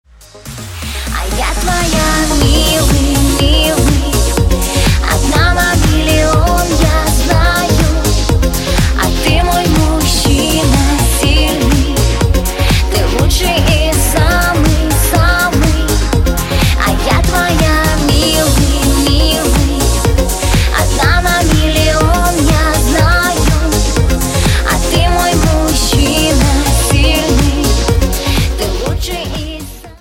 • Качество: 128, Stereo
поп
женский вокал
dance
русская попса
танцевальные